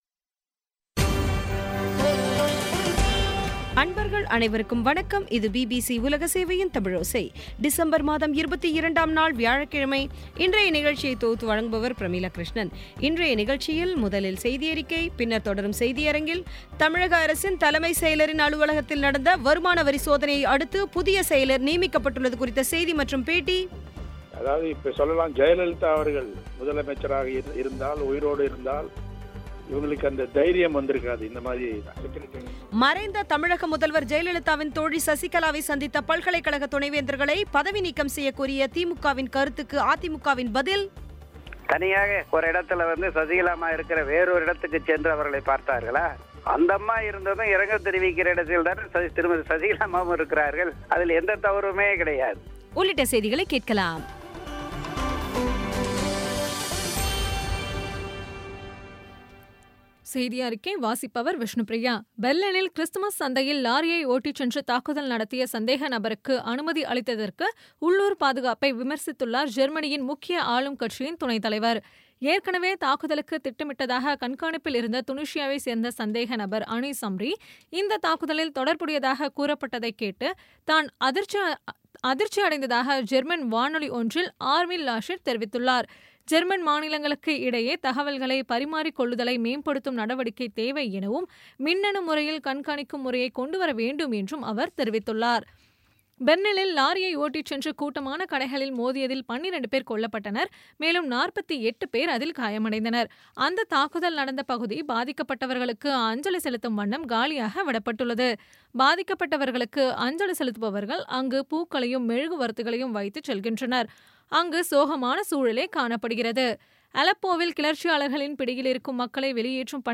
இன்றைய நிகழ்ச்சியில் முதலில் செய்தியறிக்கை, பின்னர் தொடரும் செய்தியரங்கில் தமிழக அரசின் தலைமைச் செயலரின் இல்லம் மற்றும் அலுவகத்தில் நடந்த வருமான வரிச் சோதனையை அடுத்து, புதிய செயலர் நியமிக்கப்பட்டுள்ளது குறித்த செய்தி மறைந்த தமிழக முதல்வர் ஜெயலலிதாவின் தோழி சசிகலாவை சந்தித்த பல்கலைக்கழகத் துணை வேந்தர்களை பதவி நீக்கம் செய்ய கோரிய திமுகவின் கருத்துக்கு அதிமுகவின் பதில் ஆகியவை கேட்கலாம்.